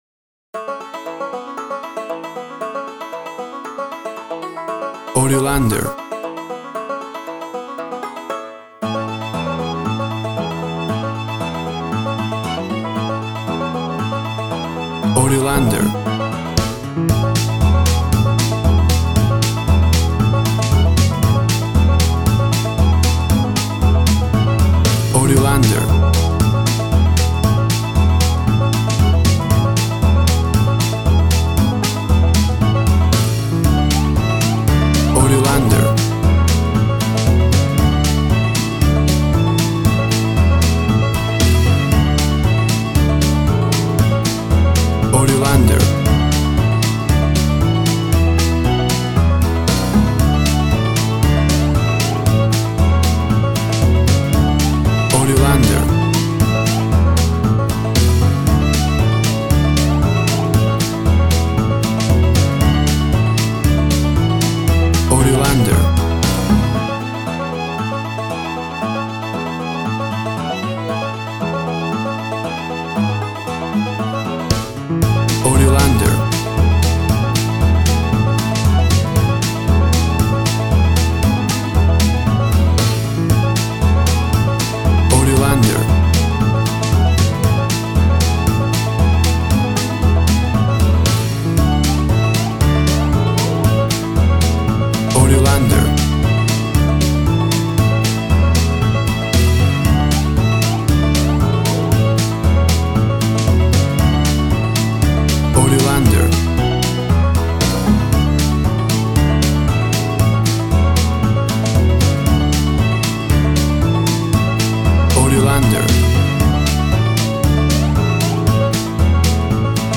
Classic country music sound.
Tempo (BPM) 115